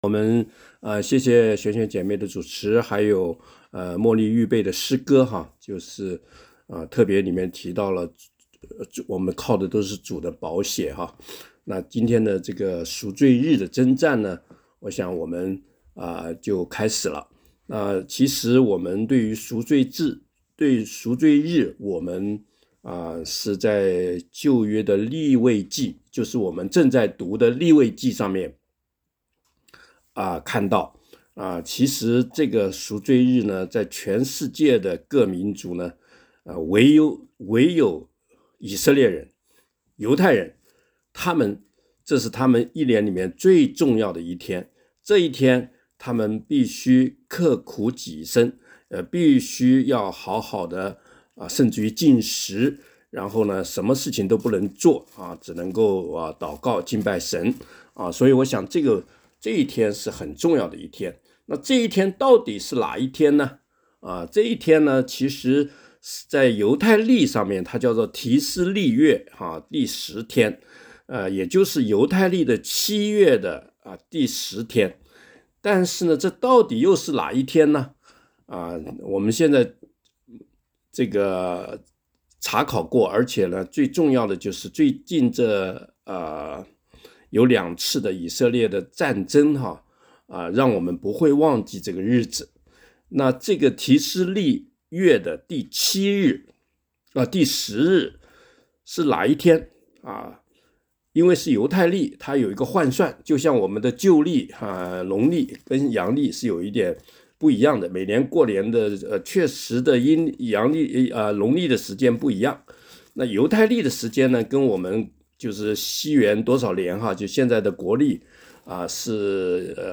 2024年7月10日主日
感谢主又让我们在空中团聚。